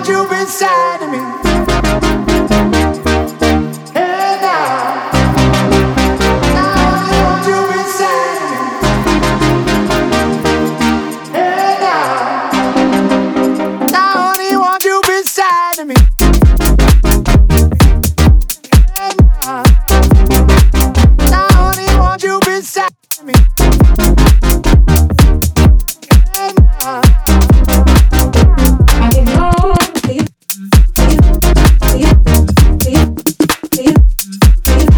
Electronic
Жанр: Электроника